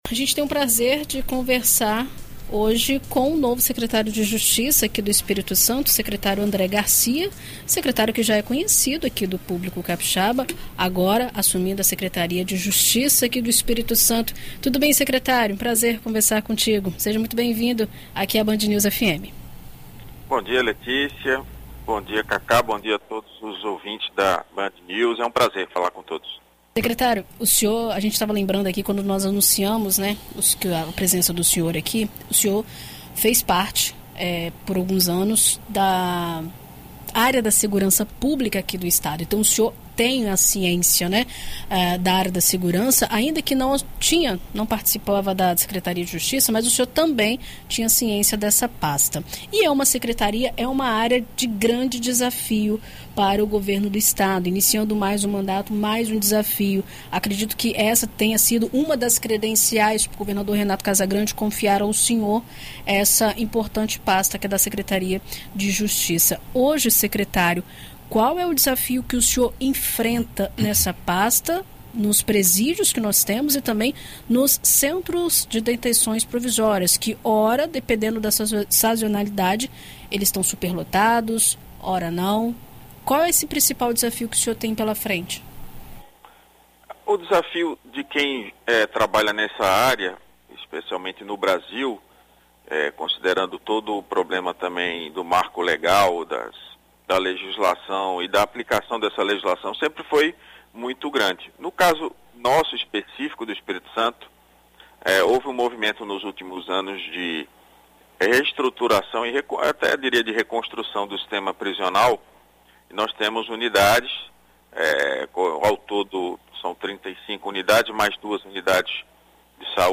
Em entrevista a BandNews FM ES nesta quarta-feira (11), o Secretário da Justiça do ES, André Garcia, fala sobre como está a situação dos presídios do estado e também sobre a abertura do concurso para inspetor penitenciário.
ENT-SECRETÁRIO-JUST.-ANDRÉ-GARCIA.mp3